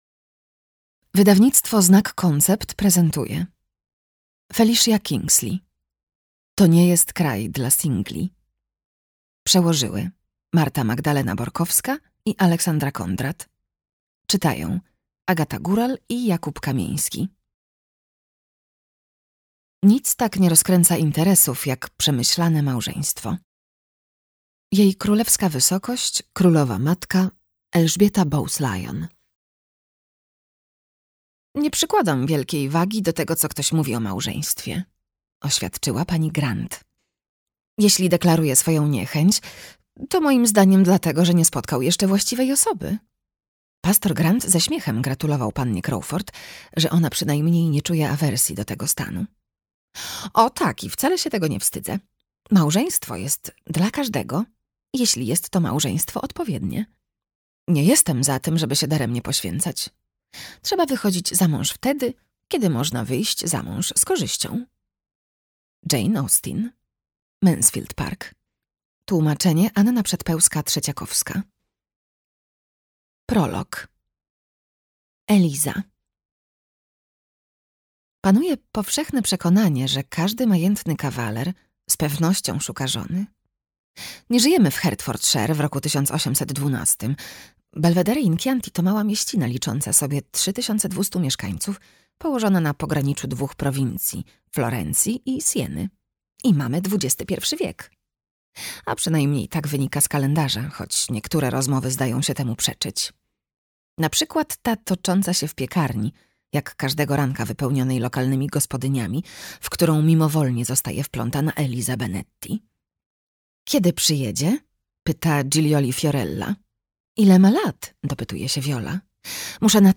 Audiobook + książka To nie jest kraj dla singli, Felicia Kingsley.